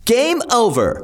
男声说gameover 音效_人物音效音效配乐_免费素材下载_提案神器